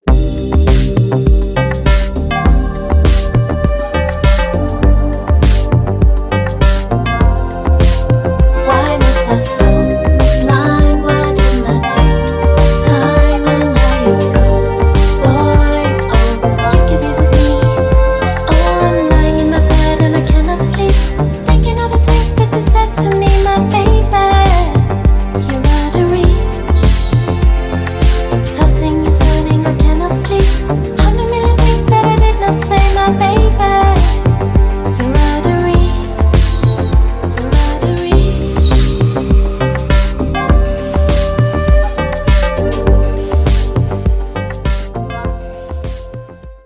красивые
dance
vocal